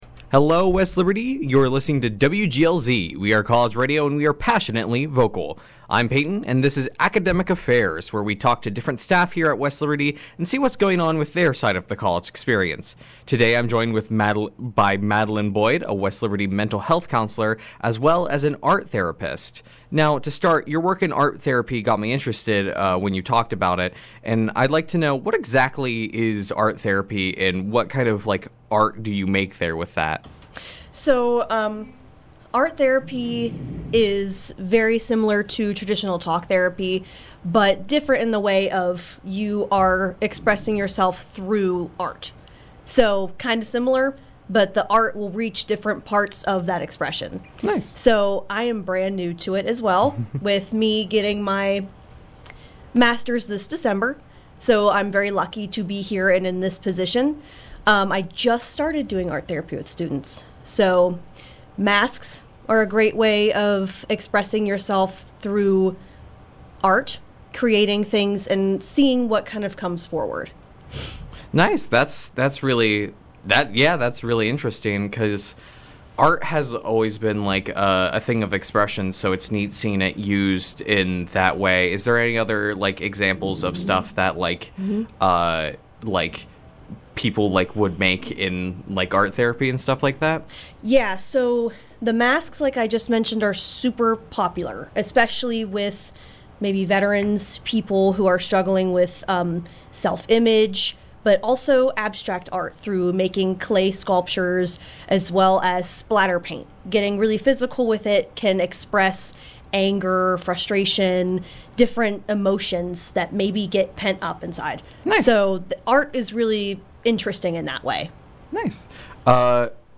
This is a part of the Academic Affairs line of interviews where WGLZ hosts interview staff and see what goes on with them and what the semester has in store for them.